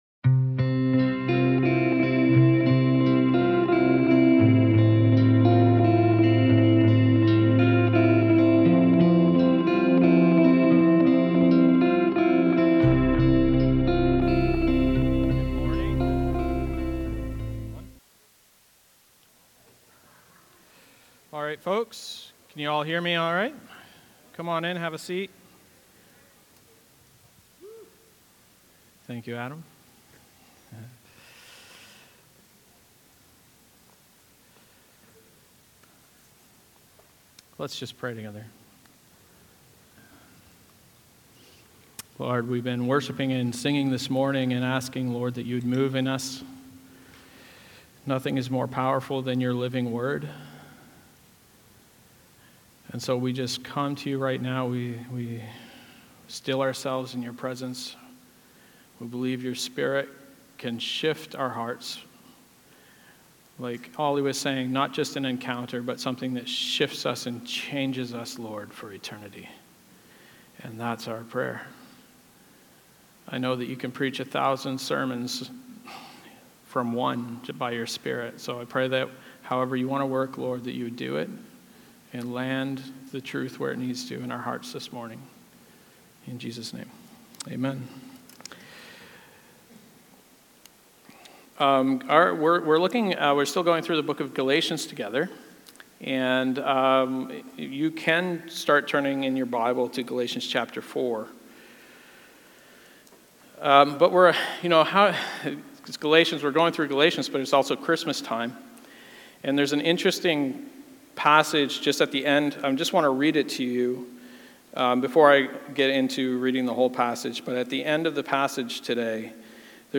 Sermons | Christ Central Church